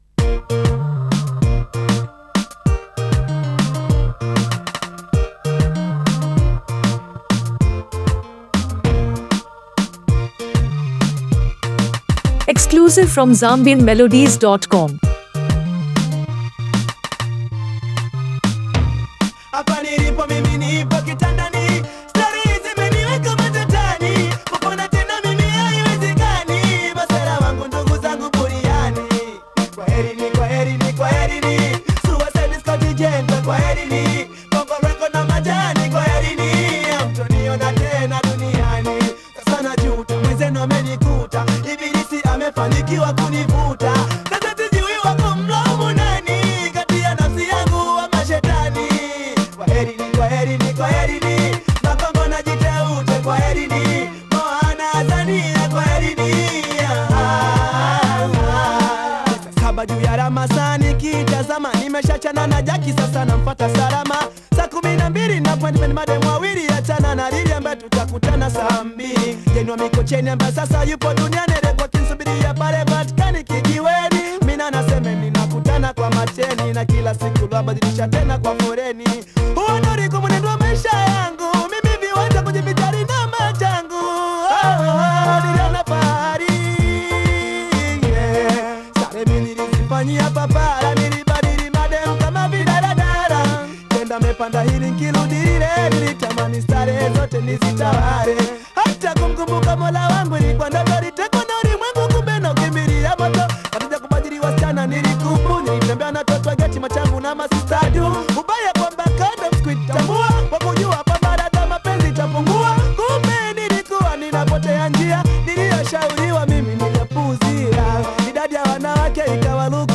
A Tanzanian Hip-Hop Masterpiece
a vibrant and uplifting song
smooth delivery and charismatic style